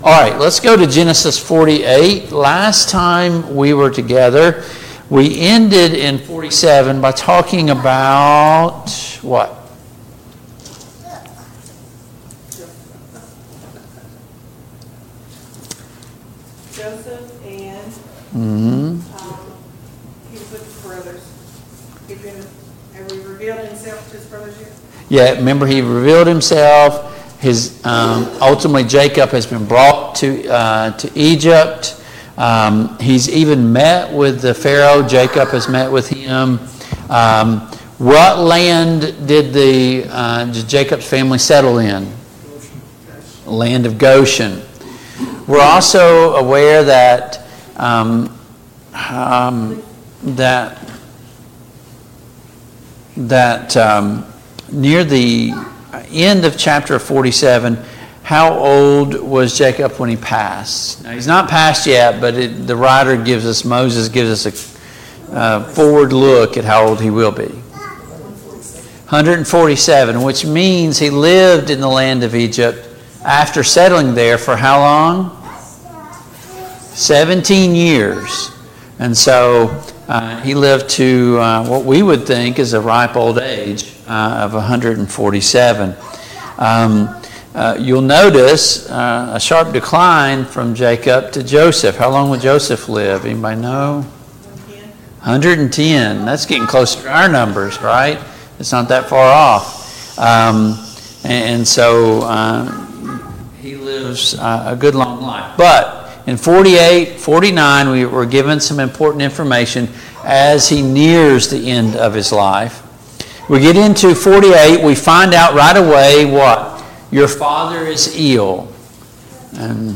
Service Type: Family Bible Hour Topics: Jacob , Joseph and his brothers , The Twelve Tribes of Israel « Is giving a necessary part of being faithful? 10.